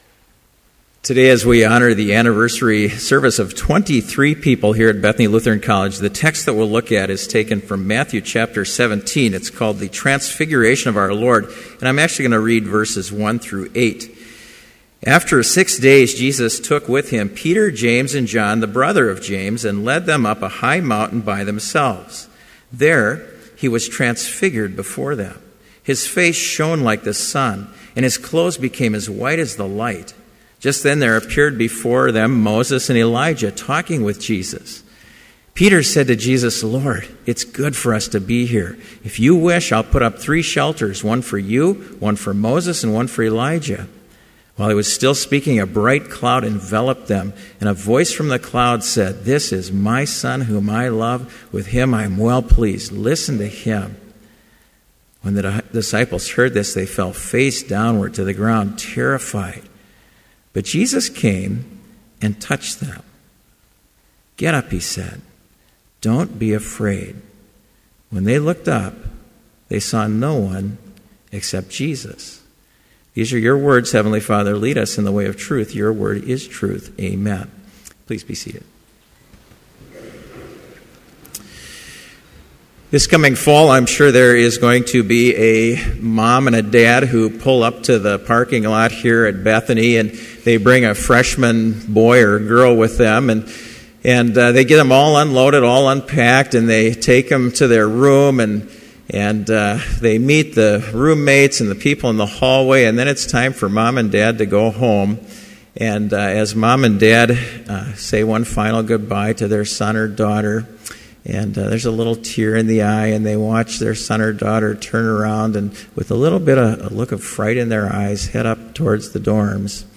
Complete service audio for Chapel - February 10, 2014